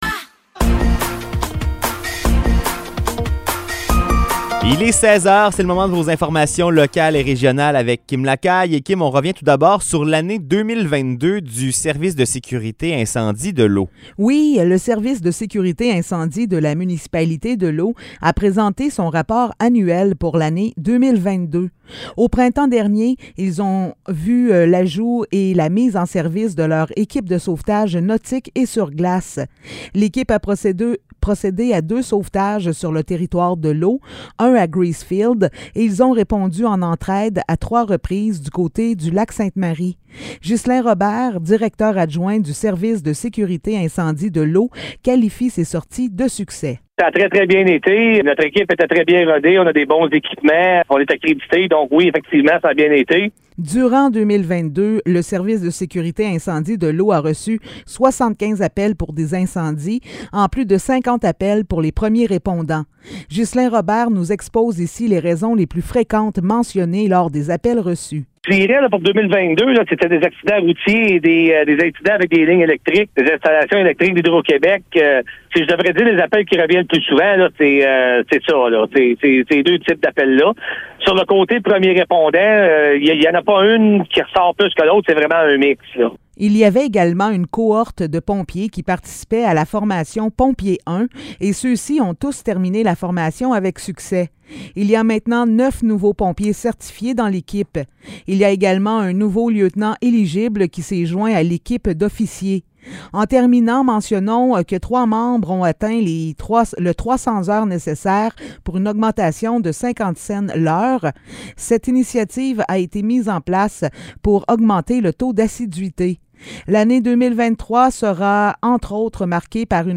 Nouvelles locales - 12 janvier 2023 - 16 h